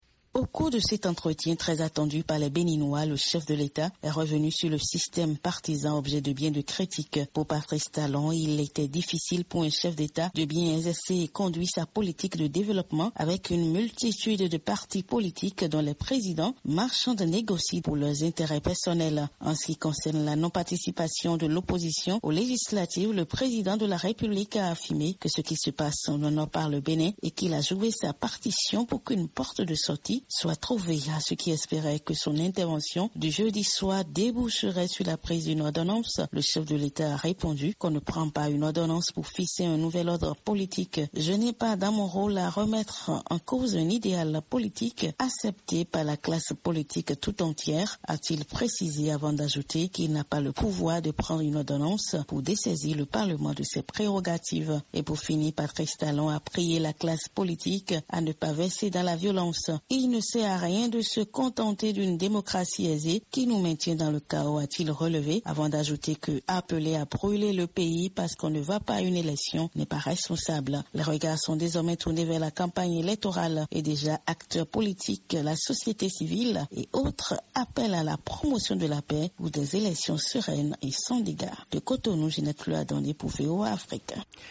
Au Bénin, la campagne électorale pour le compte des législatives démarre vendredi 12 avril. La veille au soir, le président Patrice Talon s'était adressé aux béninois au cours d'une émission diffusée sur la télévision nationale.